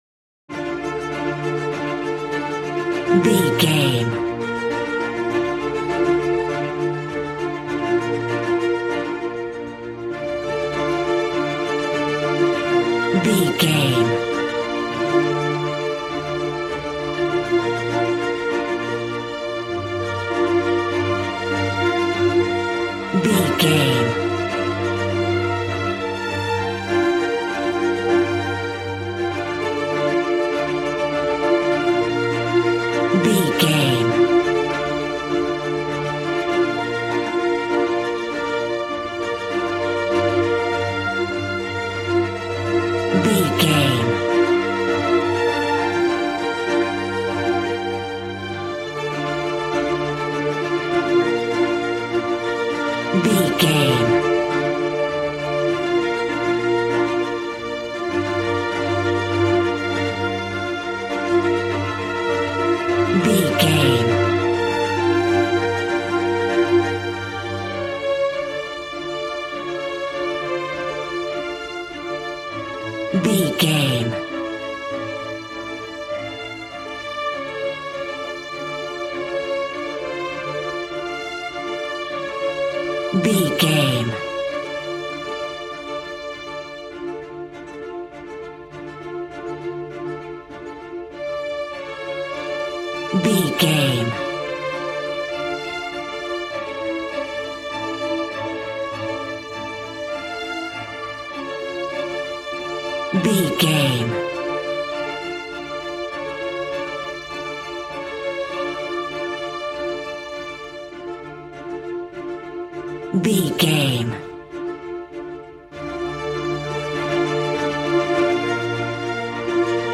Regal and romantic, a classy piece of classical music.
Aeolian/Minor
D
regal
cello
violin
strings